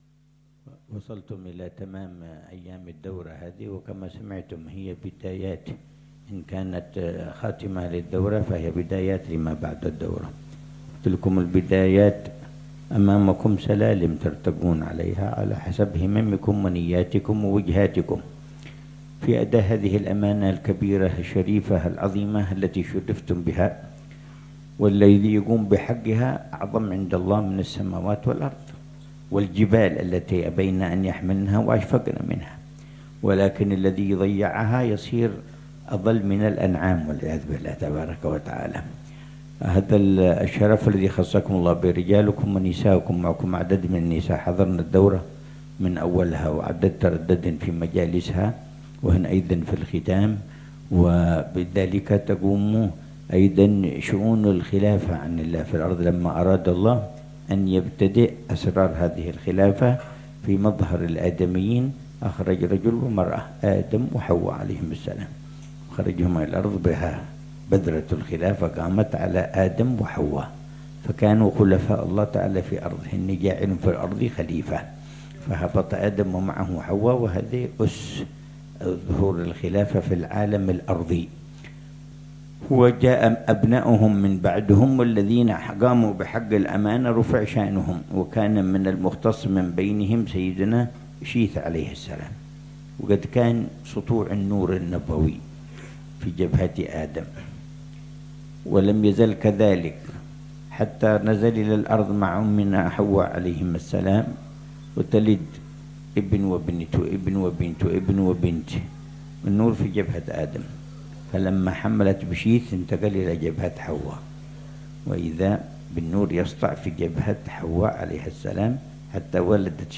كلمة العلامة الحبيب عمر بن حفيظ في مجلس توديع طلاب الدورة الصيفية الثانية بمعهد الرحمة، عمّان، الأردن، ليلة الأحد 8 ربيع الأول 1447هـ